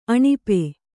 ♪ aṇipe